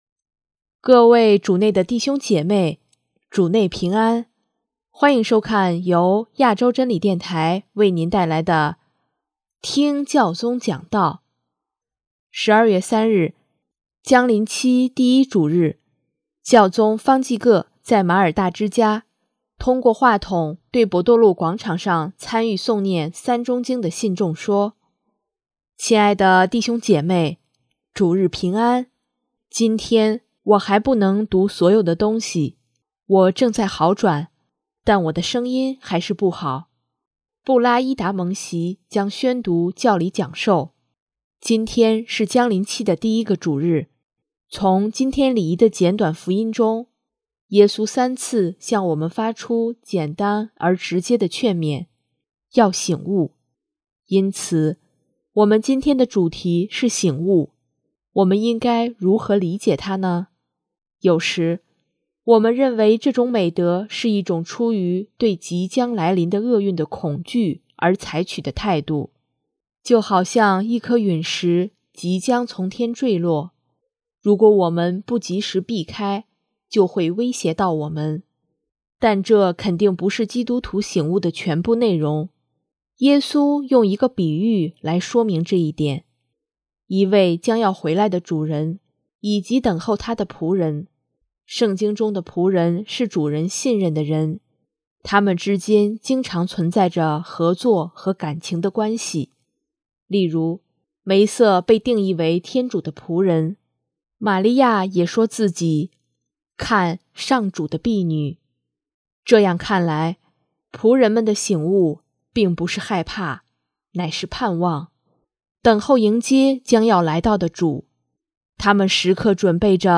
【听教宗讲道】|充满爱和期待，迎接耶稣的来临
12月3日，将临期第一主日，教宗方济各在玛尔大之家，通过话筒对伯多禄广场上参与诵念《三钟经》的信众说：